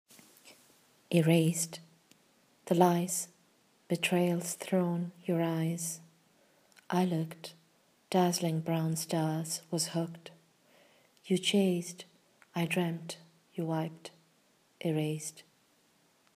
Reading of the poem